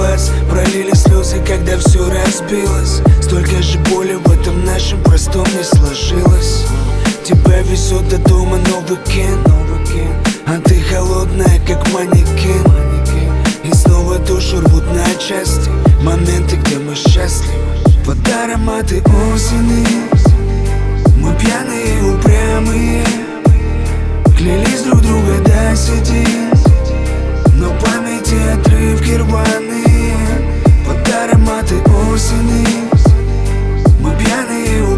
Жанр: Хип-Хоп / Рэп / Рок / Русский рэп / Русские